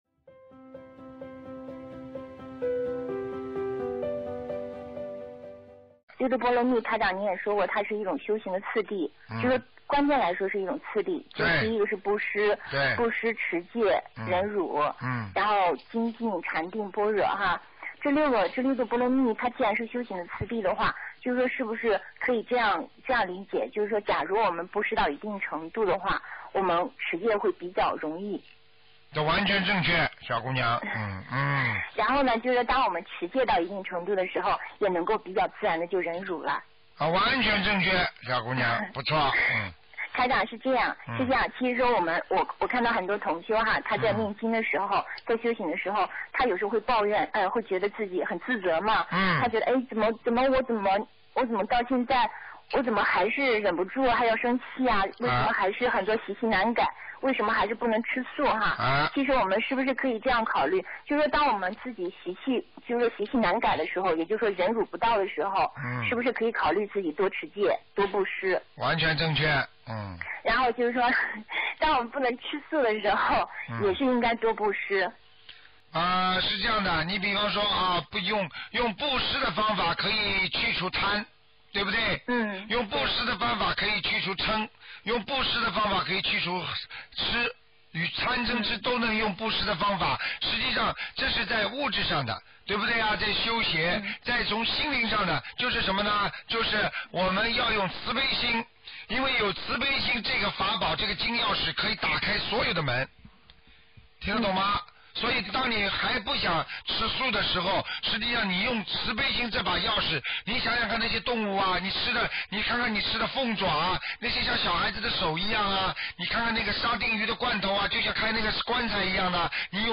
问答2012年1月1日！